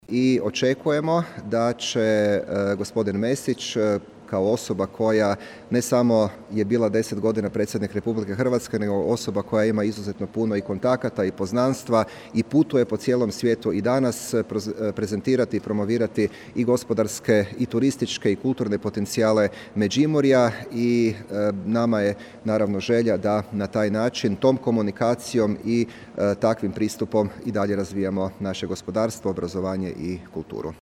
Obilazak je nastavljen razgledom Riznice Međimurja, a međimurski župan o posjeti je istaknuo: